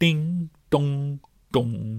描述：Beatbox创意声音/循环1小节120bpm
Tag: 创意 敢-19 循环 口技